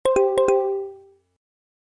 Home > Ringtones Mp3 > SMS Tone > New Collection Home